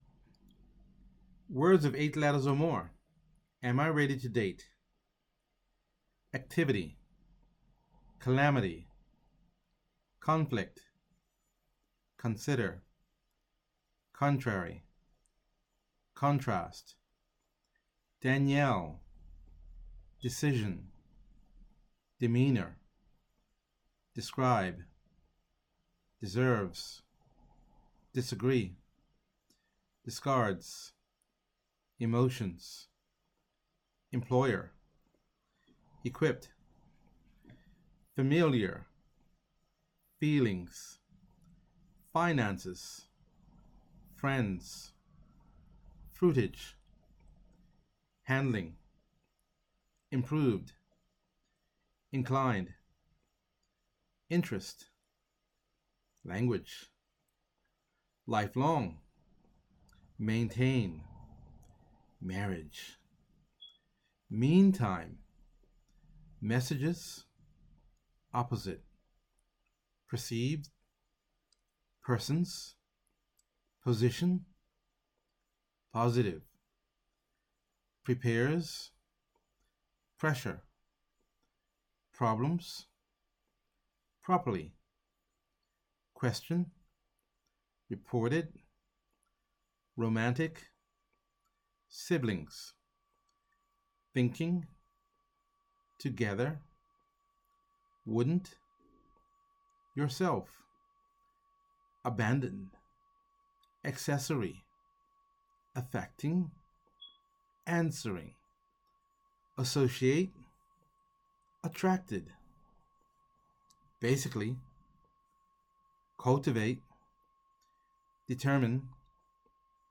Below the vocabulary list and audio pronunciation for the presentation “Am I ready for dating and marriage” for English learners.